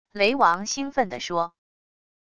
雷王兴奋地说wav音频